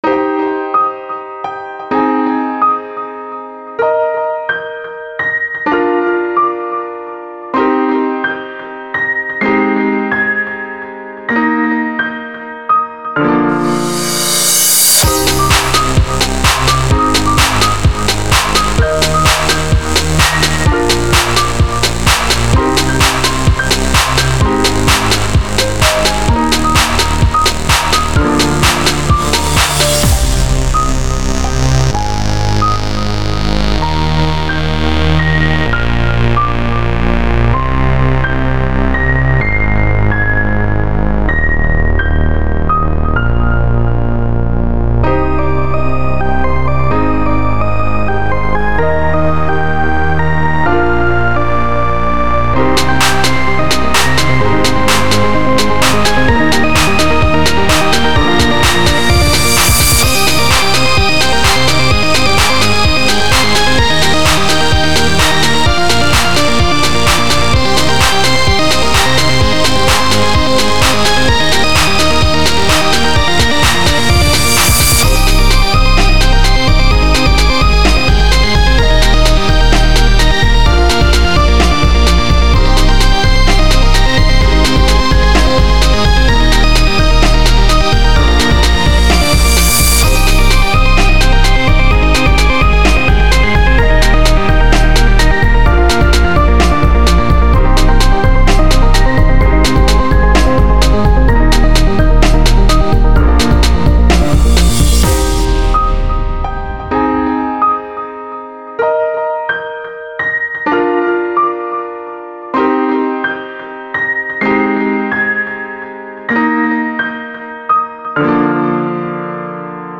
I've been really into the overused 6415 chord progression recently, I hope you guys don't mind!